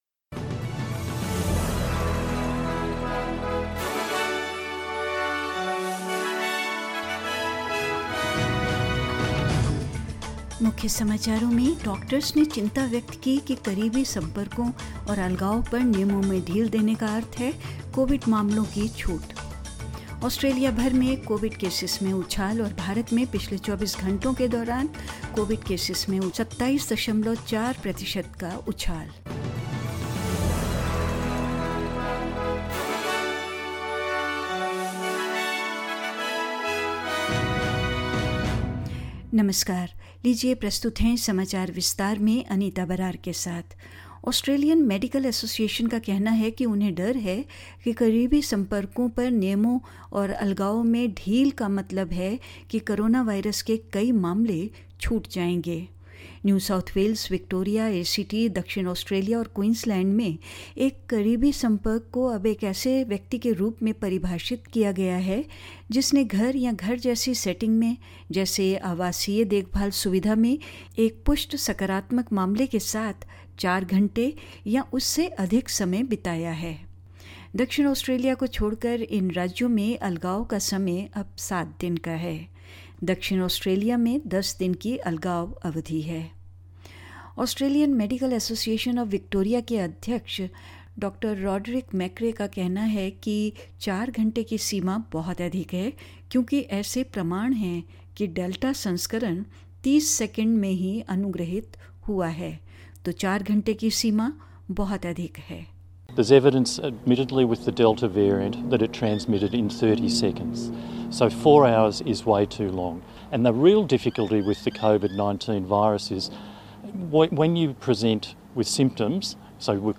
In this latest SBS Hindi News bulletin: Doctors concerned relaxed rules on close contacts and isolation will mean COVID-19 cases are missed; Record number of new cases of COVID-19; In India, COVID cases continue to rise; The Australian batsman Travis Head tests positive to COVID-19 and more news